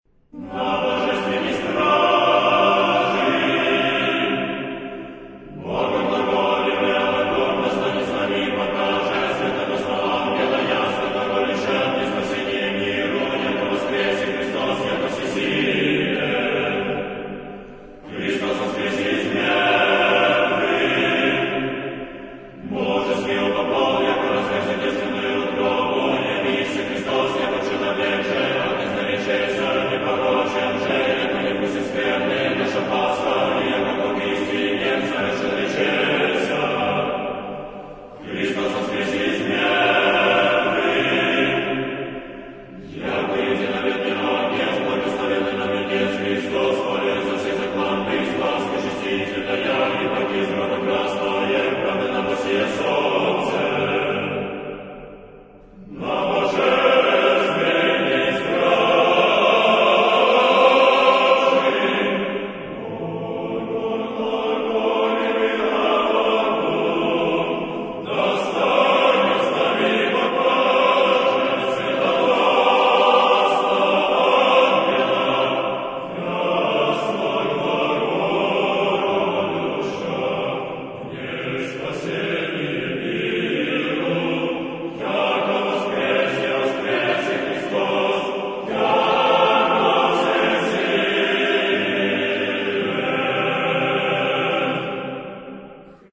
Глас 1.